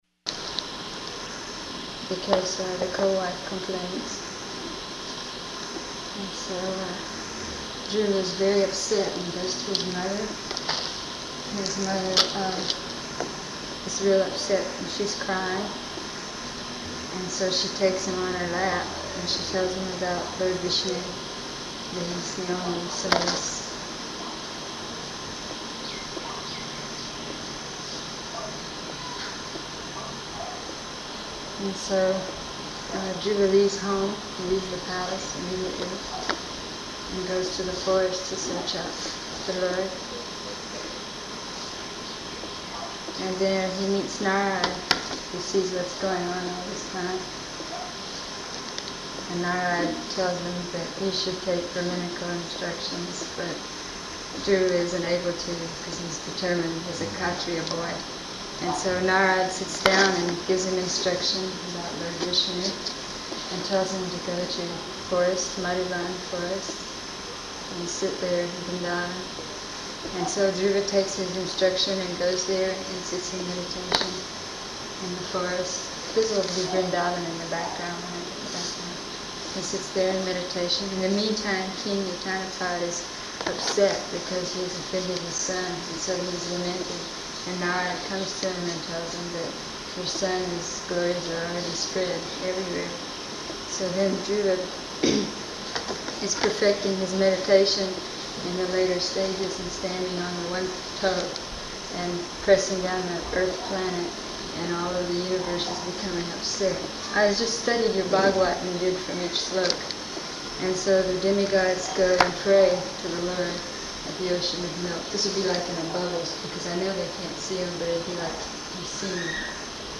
Location: Honolulu
Morning WalkRoom Conversation